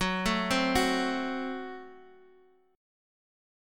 F#dim Chord